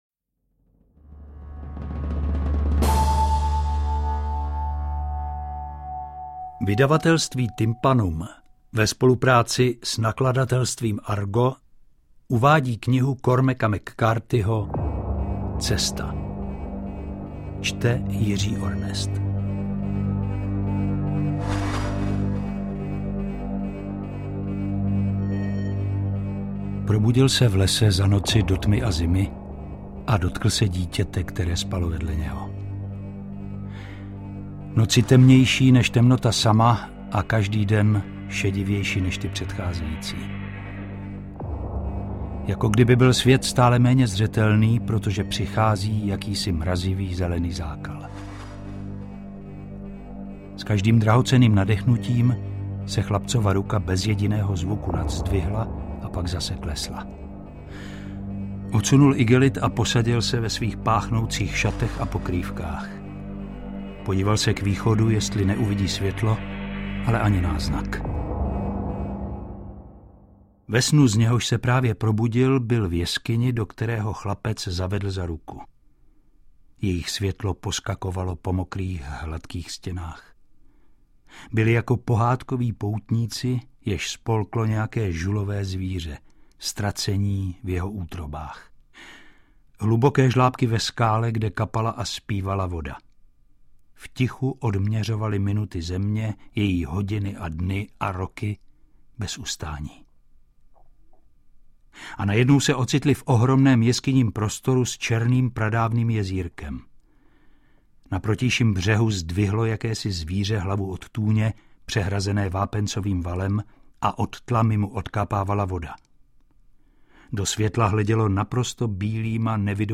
Interpret:  Jiří Ornest
V kombinaci se zvoleným hudebním doprovodem je to zkrátka neskutečný nářez!